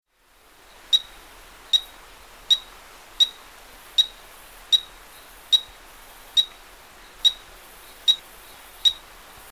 18 Epipedobates Bolivianus.mp3